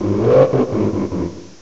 cry_not_oranguru.aif